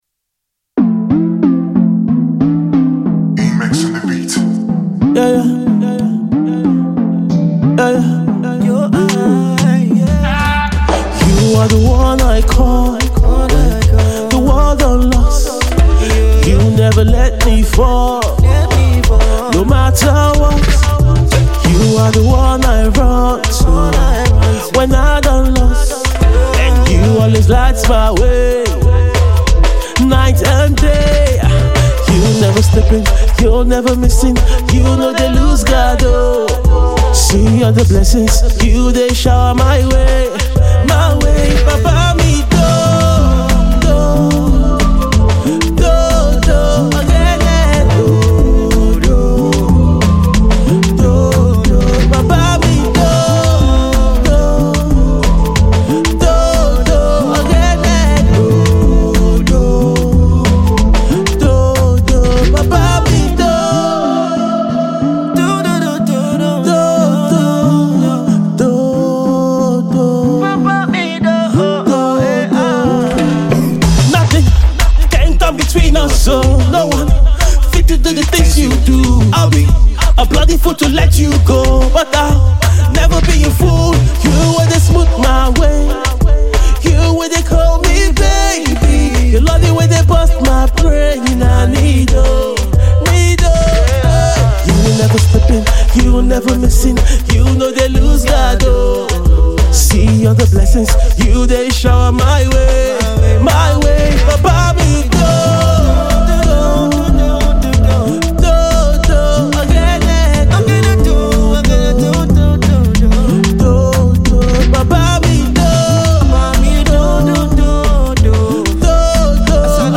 a Christian Afropop/Dancehall tune praise banger.